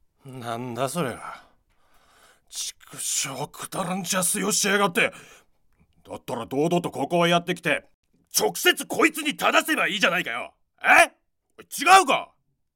ボイスサンプル
セリフ@